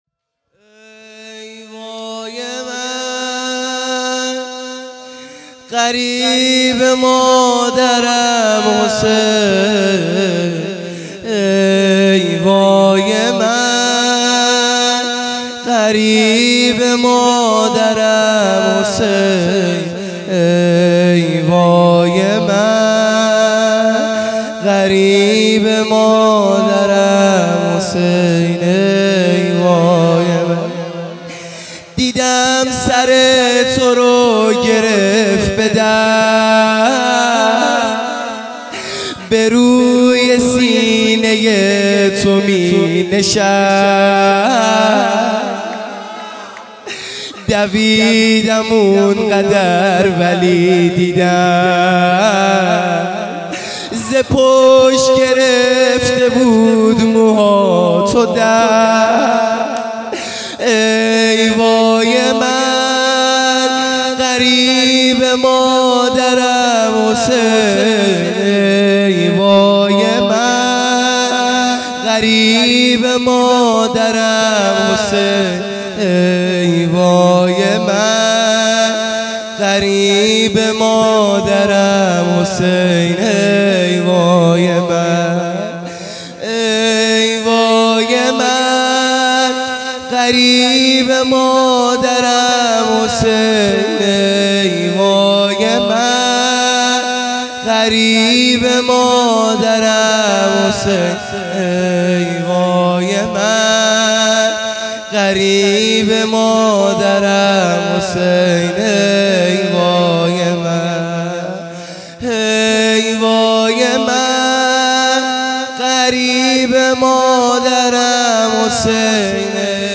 0 0 زمینه | ای وای من غریب
شب دهم محرم الحرام ۱۳۹۶